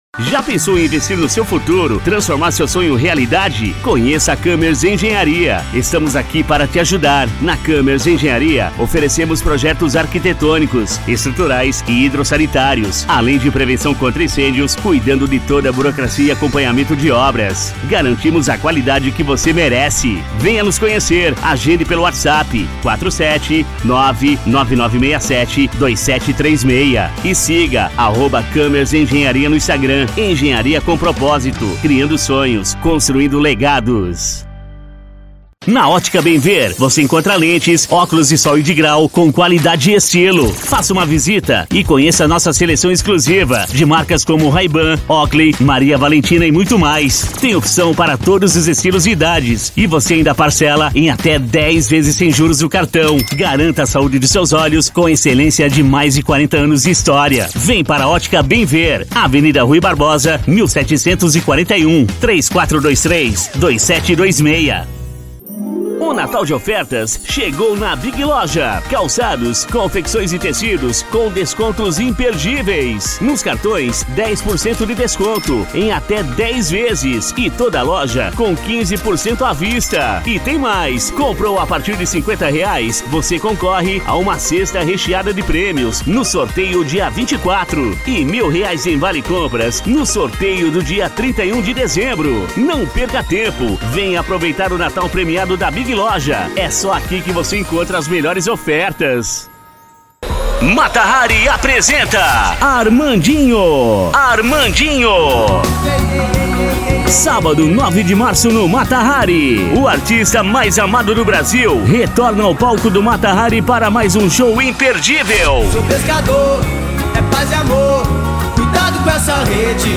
Impacto
Animada